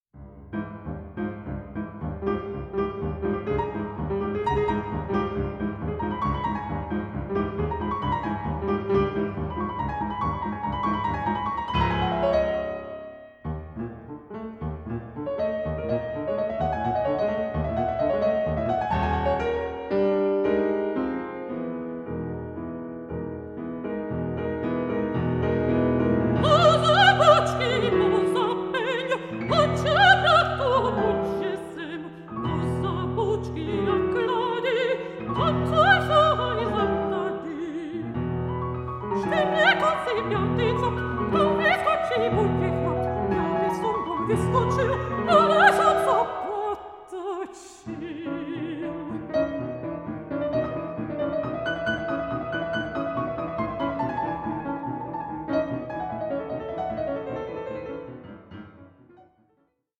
pianist
mezzo-soprano